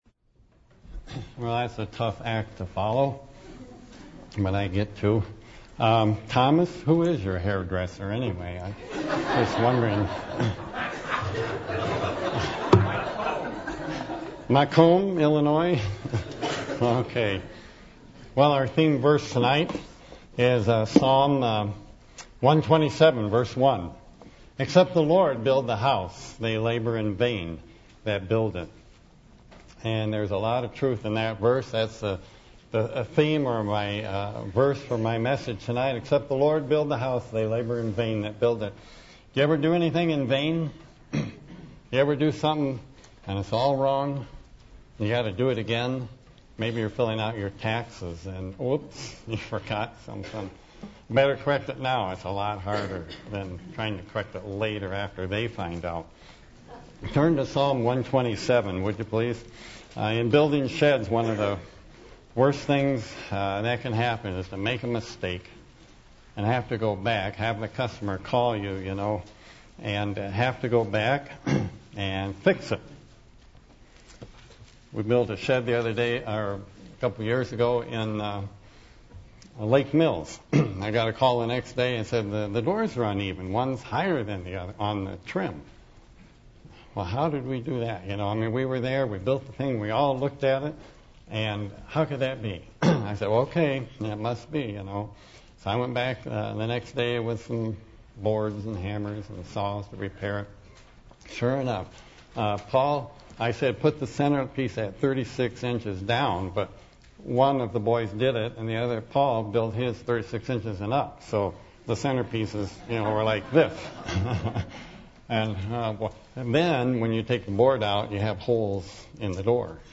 Psalm 127:1-2 Service Type: Sunday Evening %todo_render% « Family Day